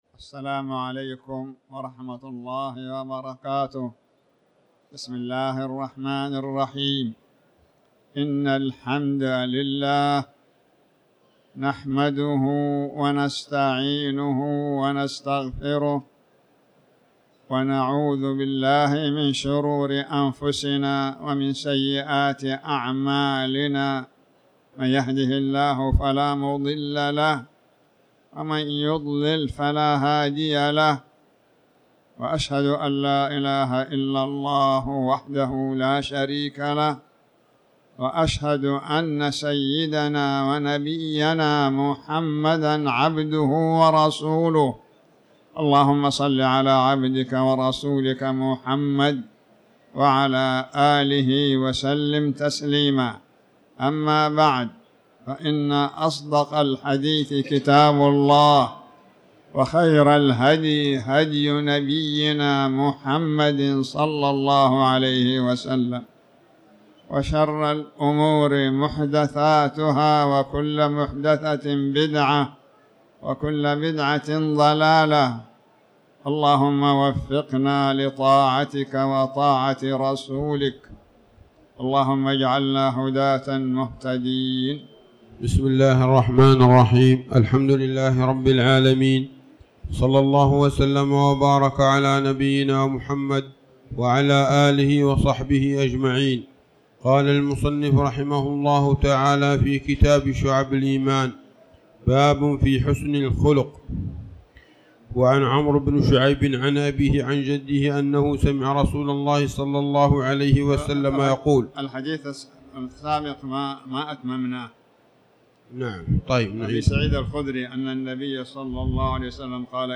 تاريخ النشر ٣ رجب ١٤٤٠ هـ المكان: المسجد الحرام الشيخ